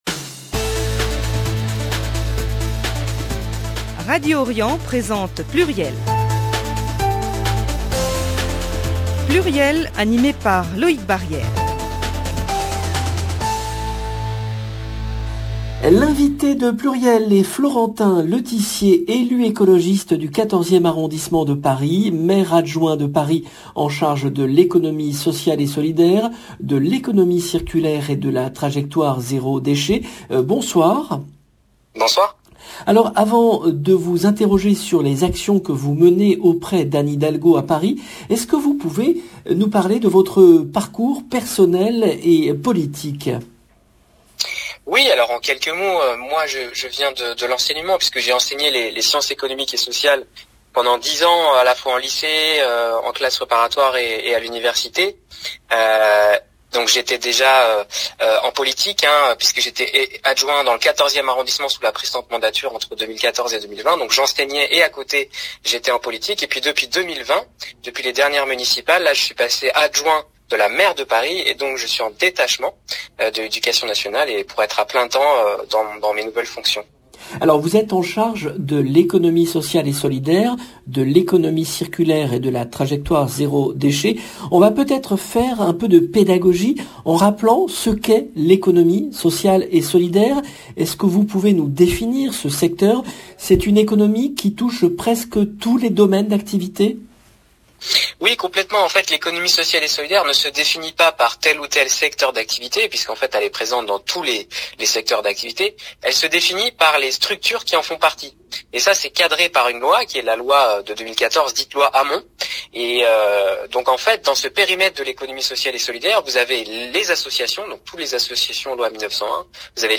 L’invité de PLURIEL est Florentin Letissier , élu écologiste du 14e arrondissement de Paris, Maire-adjoint de Paris en charge de l’économie sociale et solidaire, de l’économie circulaire et de la trajectoire zéro déchet.